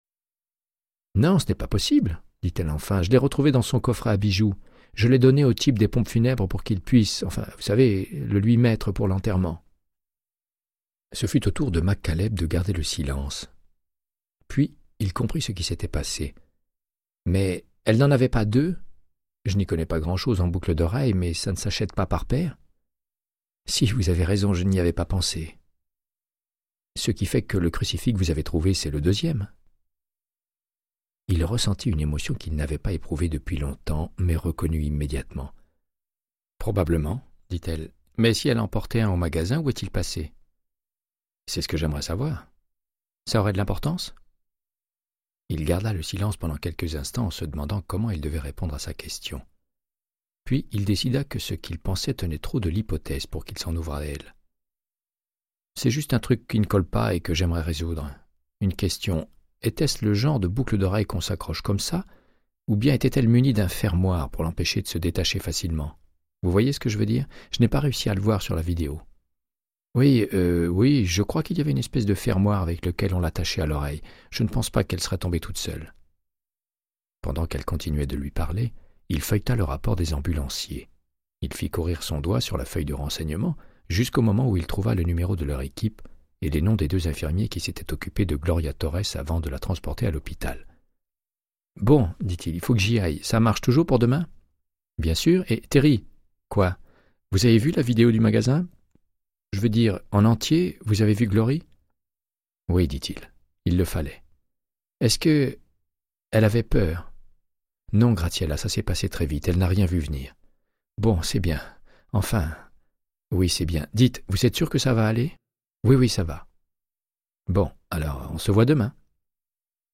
Audiobook = Créance de sang, de Michael Connelly - 76